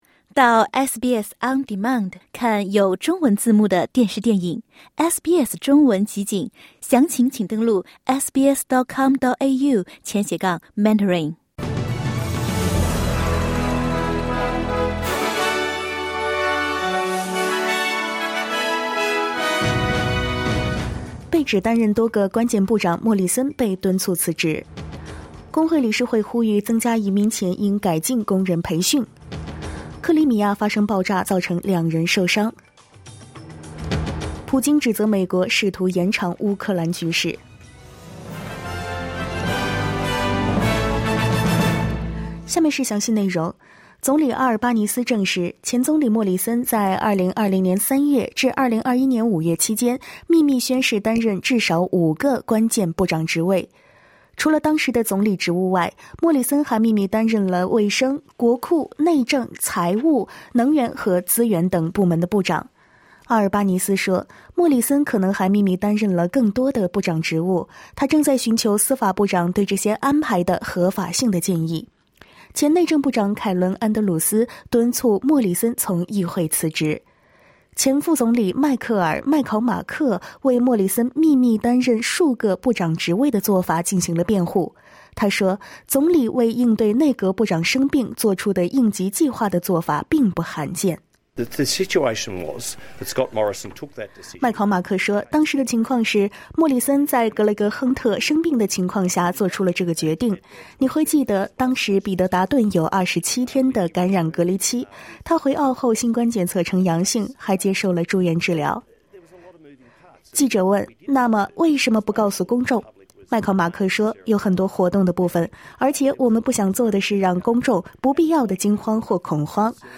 请点击收听SBS普通话为您带来的最新新闻内容。